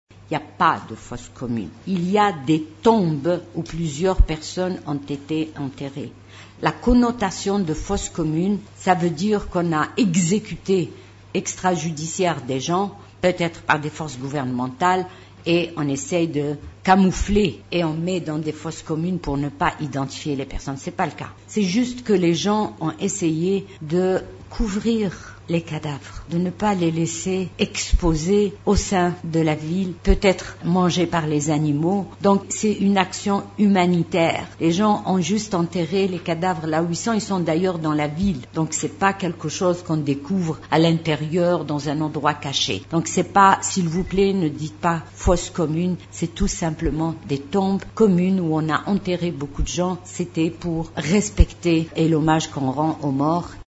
Le peuple congolais a donné une leçon extraordinaire de sa prise de conscience et de maturité, a affirmé mercredi 30 janvier la Représentante spéciale du Secrétaire général de l’ONU en RDC, Leila Zerrougui, au cours de sa première conférence de presse de 2019 à Kinshasa.